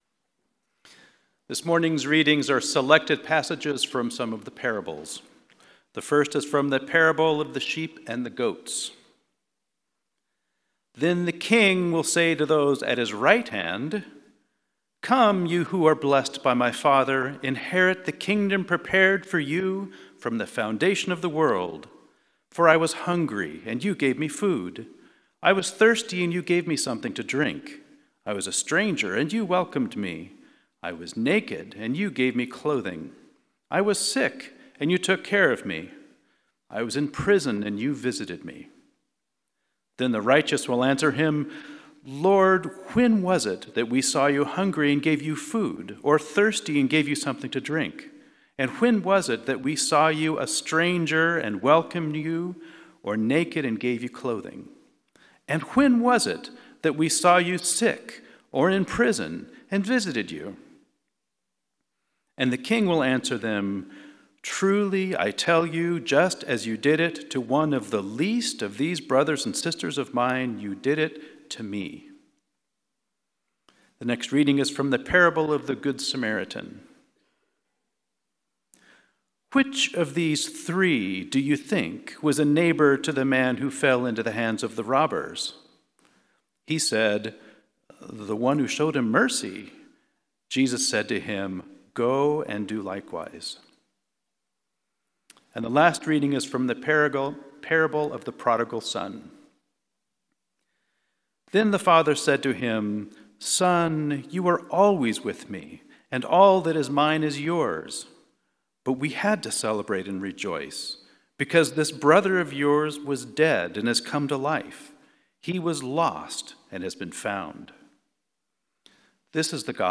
Sermon – Methodist Church Riverside
Fourth Sunday in Lent sermon